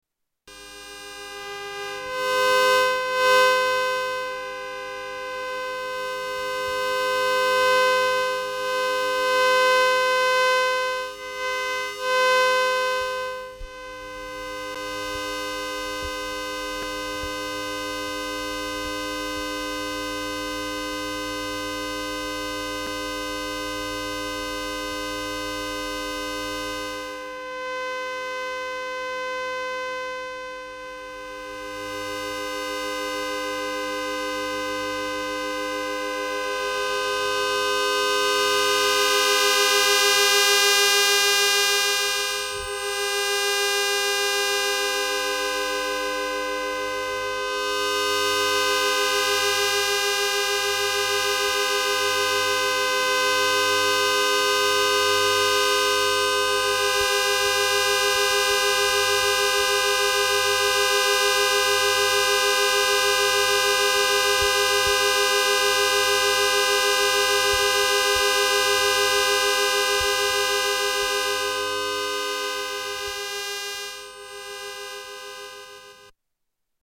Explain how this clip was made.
Tags: Travel Sounds of Austria Austria Holidays Vienna